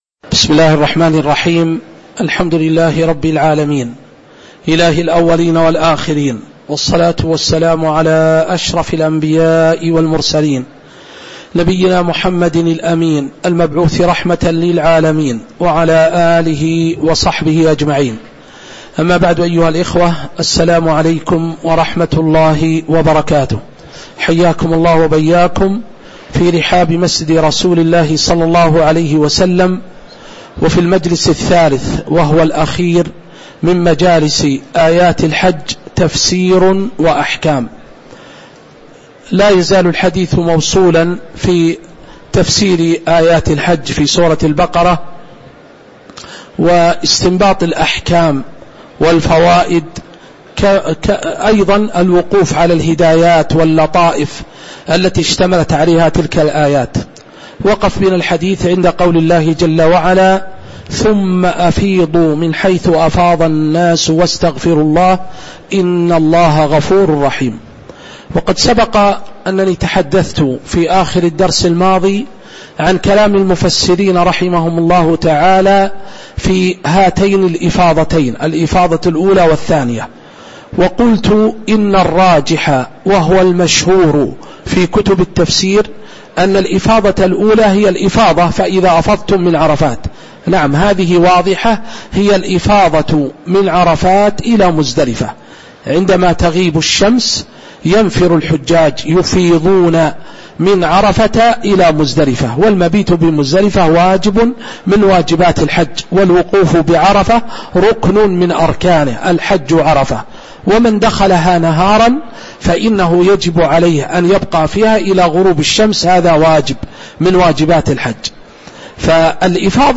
تاريخ النشر ٢٧ ذو القعدة ١٤٤٦ هـ المكان: المسجد النبوي الشيخ